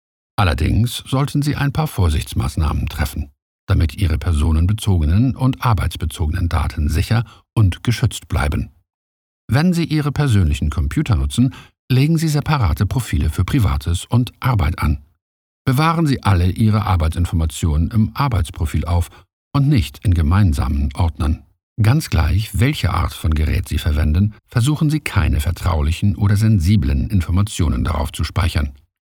Deutscher und englischer Sprecher mit charismatischer, tiefer Stimme für Werbung, Film, Fernsehen, Synchronisation, Radio, Corporate Film, Audio Touren und E-Learning.
Sprechprobe: Industrie (Muttersprache):